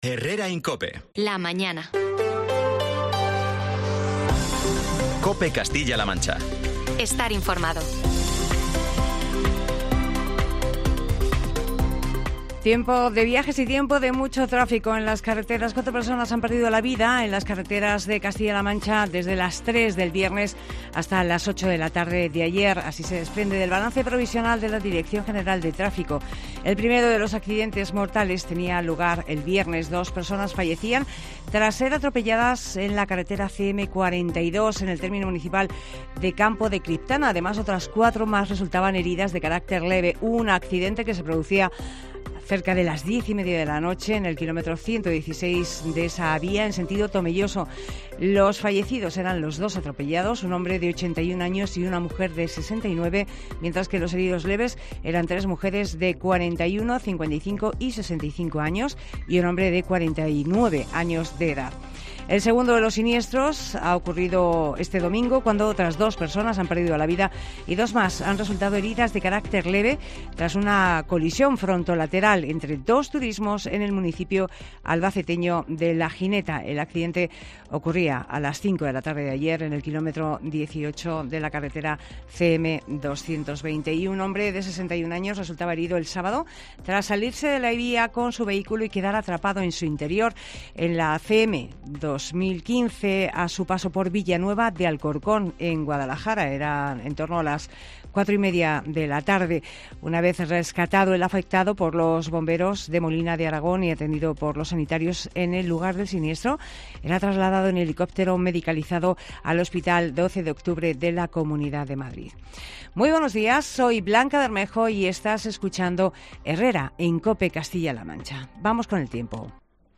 INFORMATIVO